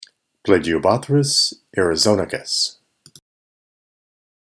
Pronunciation/Pronunciación:
Pla-gi-o-bó-thrys a-ri-zò-ni-cus